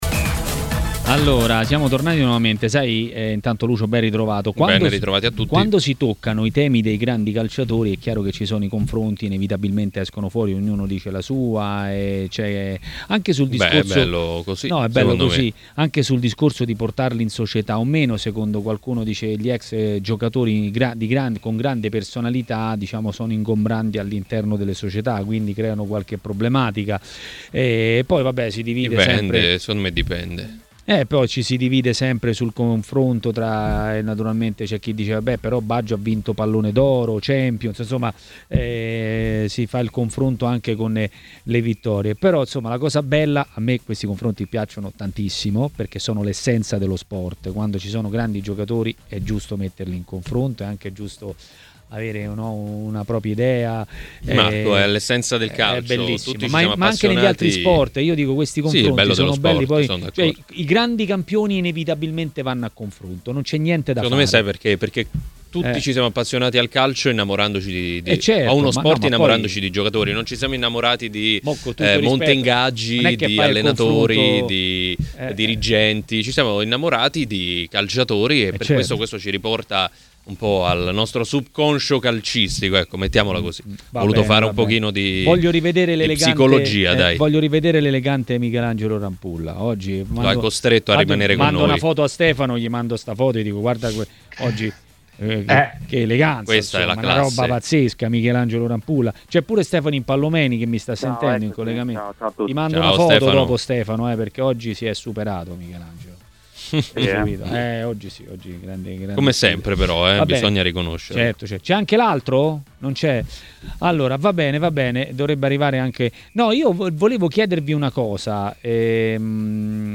Ospite di Maracanà, nel pomeriggio di TMW Radio, è stato l'ex calciatore Jeda.